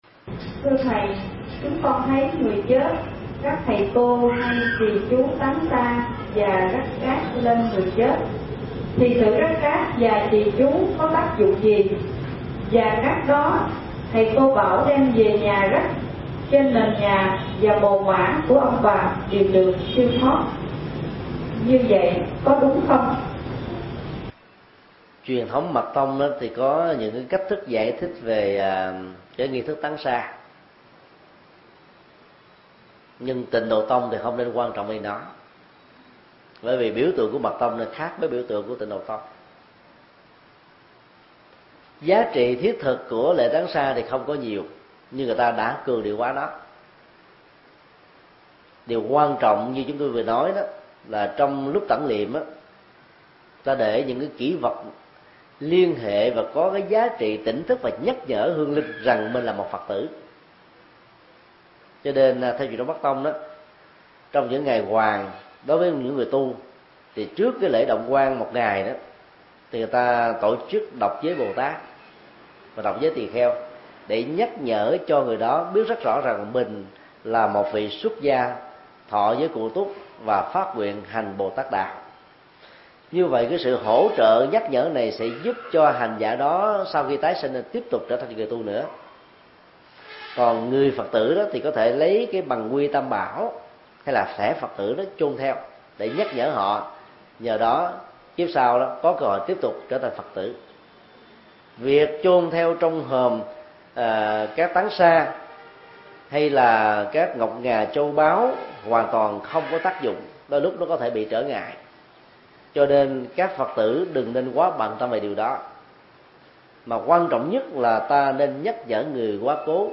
Vấn đáp